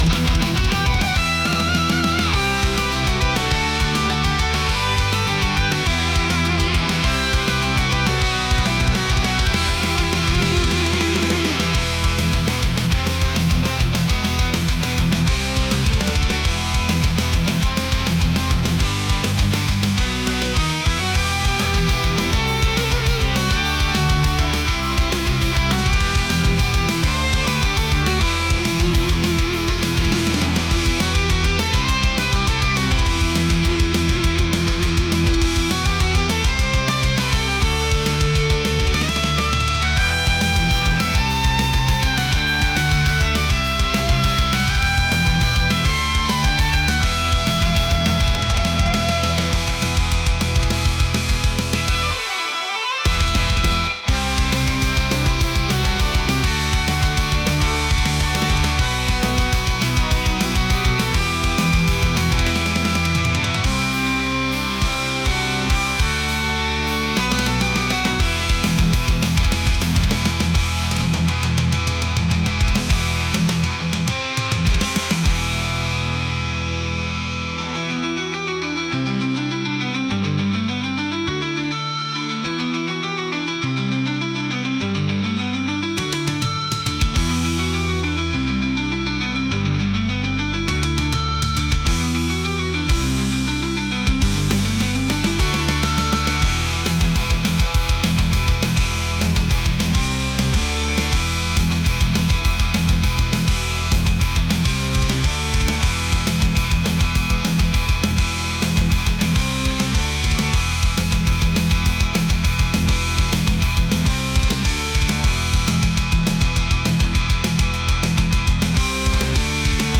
metal | intense